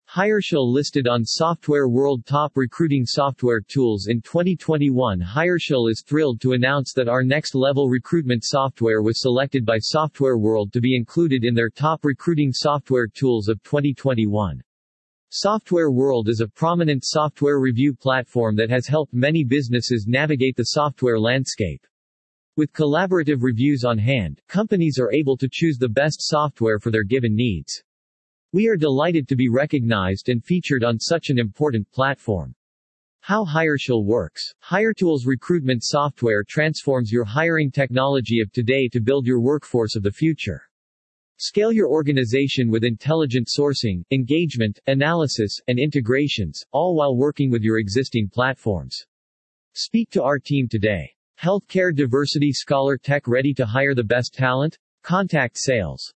You can use this audio player to convert website page content into human-like speech.